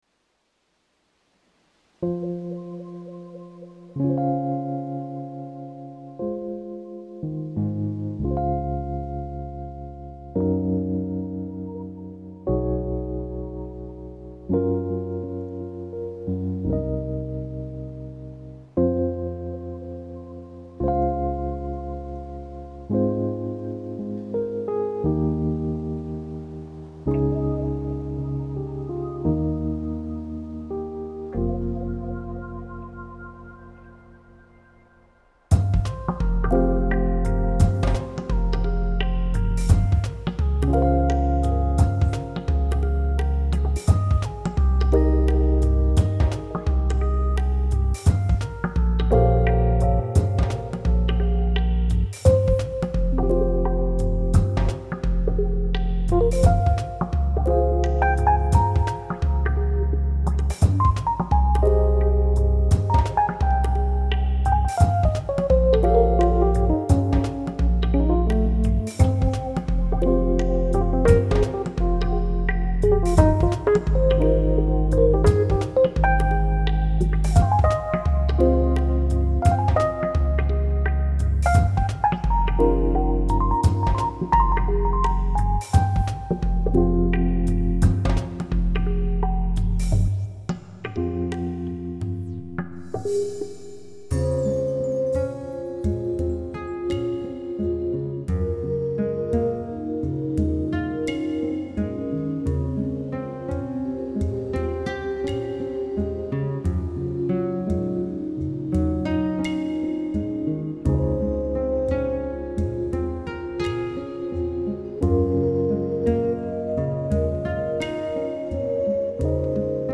It's more trippy, and the moon is blue this time.
Download Song - Downtempo / Chill